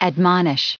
20_admonish.ogg